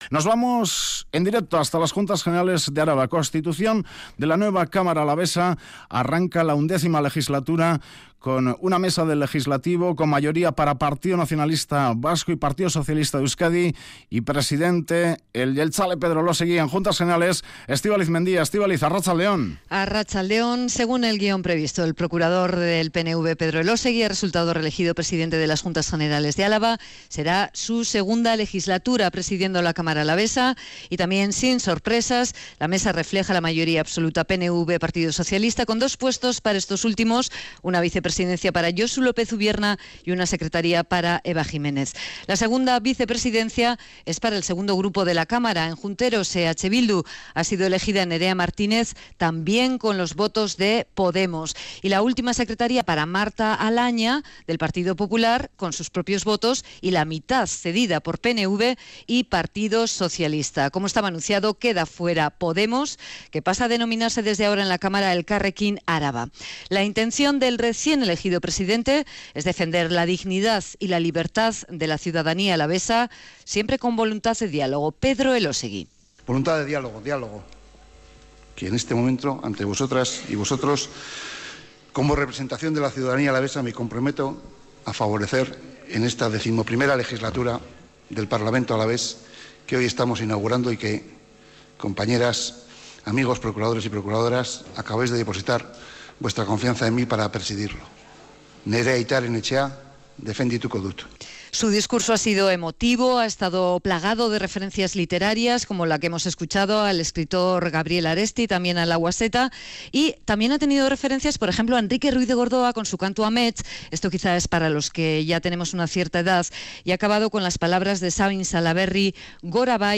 Audio: Aquí puedes escuchar las palabras de Pedro Elosegi(PNV) reelegido presidente de las Juntas Generales de Araba. Podemos se ha quedado fuera de la Mesa